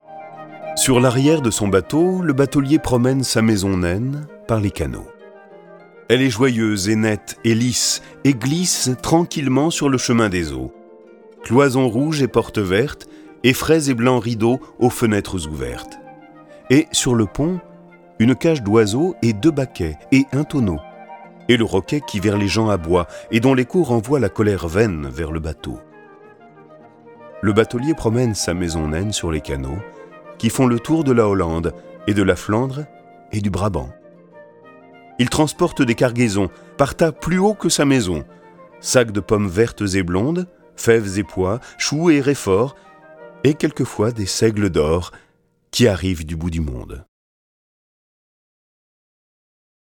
Le récit et les dialogues sont illustrés avec les musiques de Beethoven, Borodine, Chopin, Corelli, Debussy, Dvorak, Grieg, Mozart, Pergolèse, Rimsky-Korsakov, Schubert, Tchaïkovski, Telemann et Vivaldi.